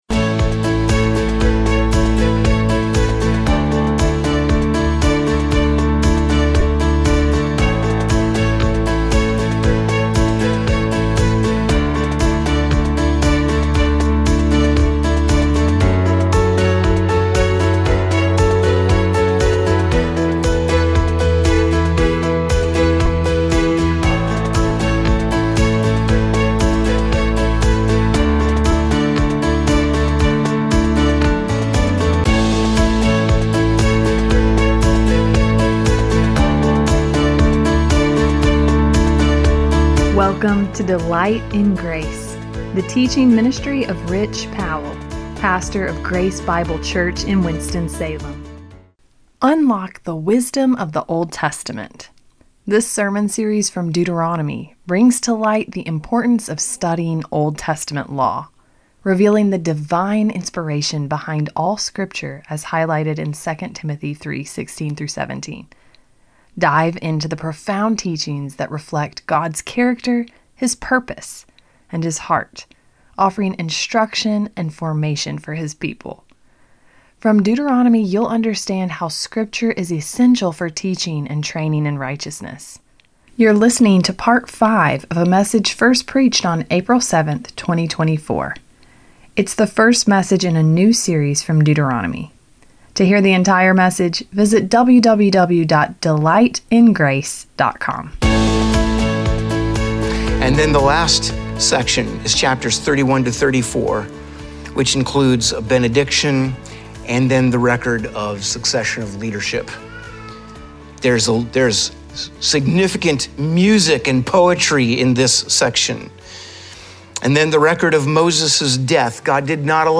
This sermon series from Deuteronomy brings to light the importance of studying Old Testament law, revealing the divine inspiration behind all Scripture as highlighted in 2 Timothy 3:16-17.